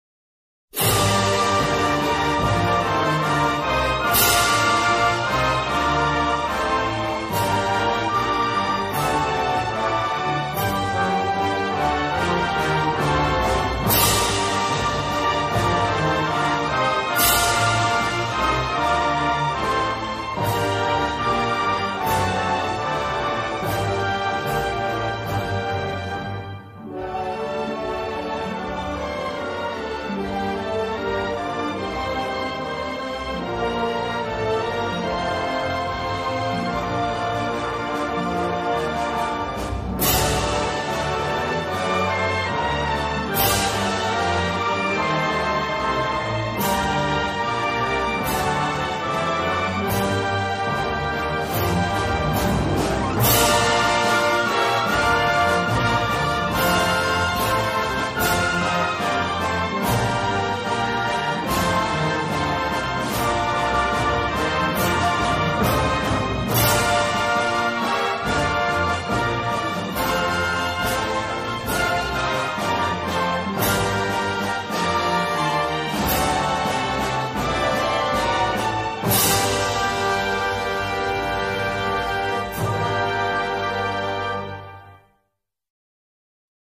• Качество: высокое
Торжественная мелодия и слова патриотической песни \